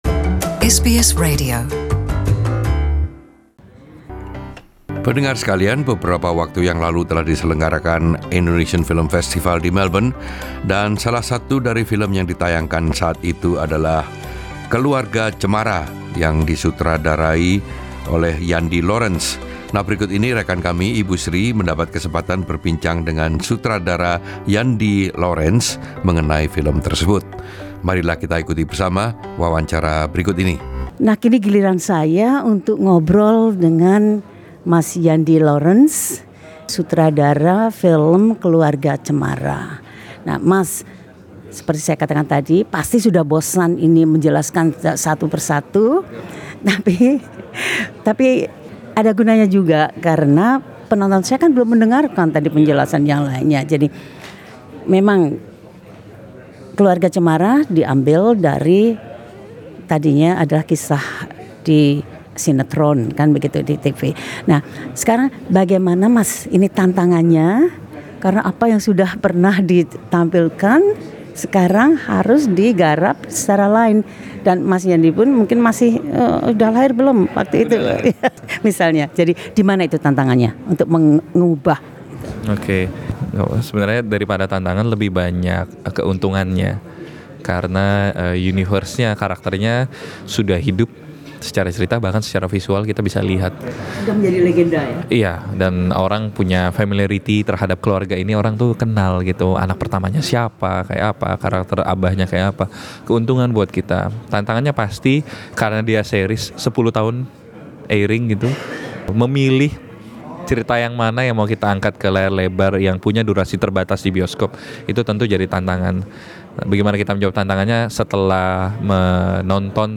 talks to SBS Indonesian after the press conference for the 14th IFF at ACMI - Melb 5 Apr 201